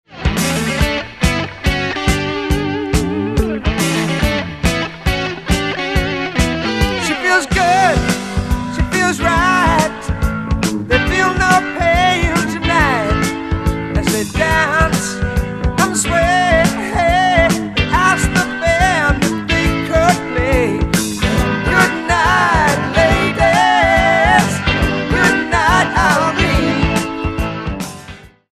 Recorded and mixed at Phase One Studios, Toronto, Canada.